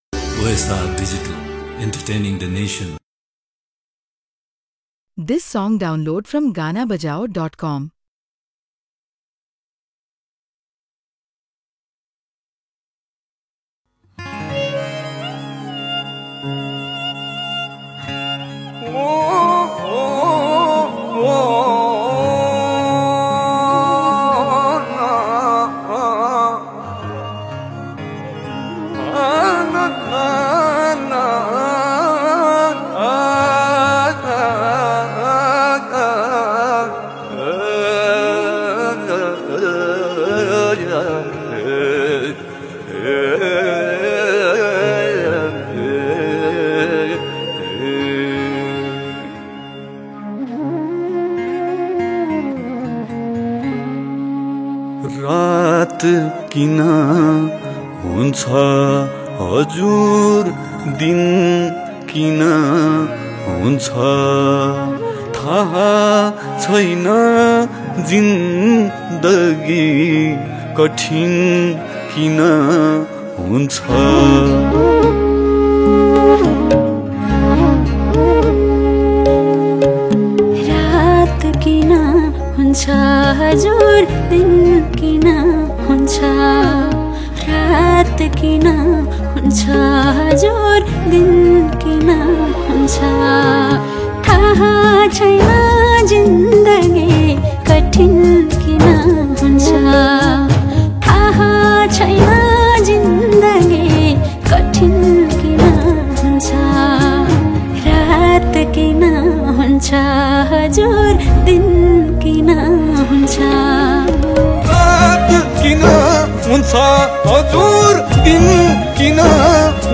Sad Song